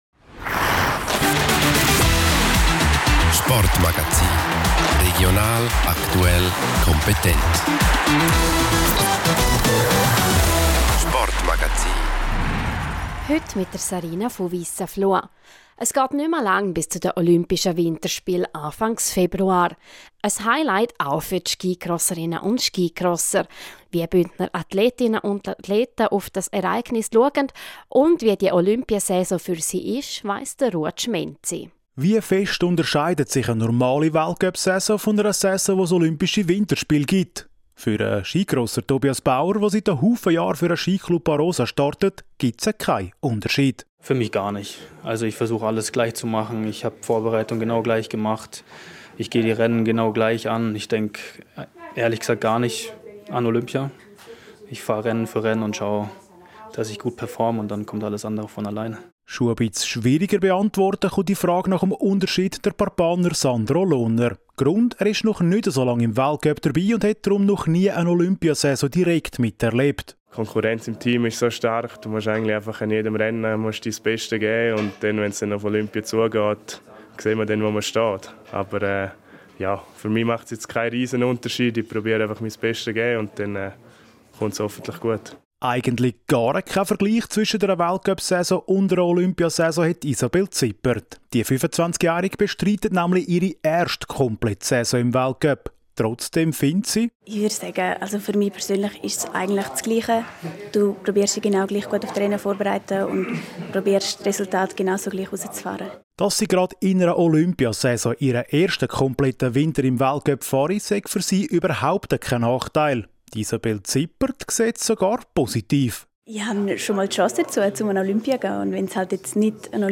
• Meldungen zu Sportlerinnen und Sportlern sowie Teams aus dem Sendegebiet.